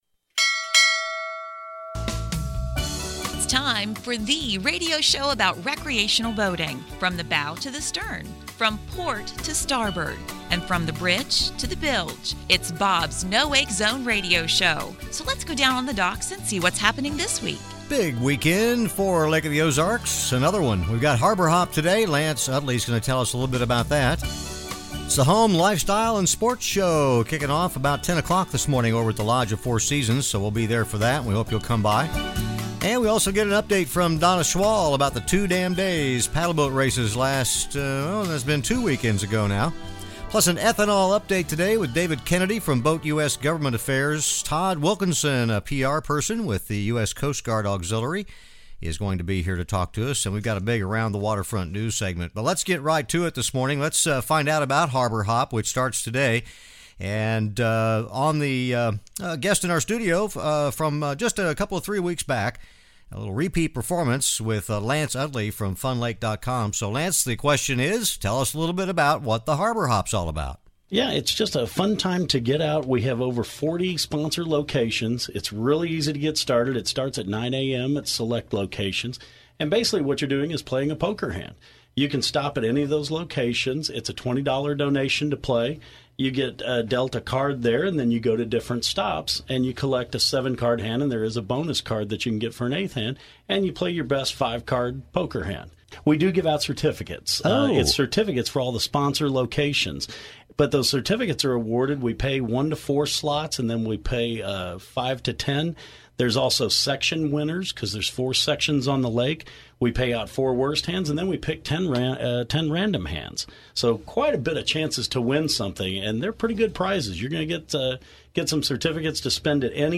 This is THE radio show about recreational boating plus it’s a terrific slice of life from Missouri’s magnificent Lake of the Ozarks.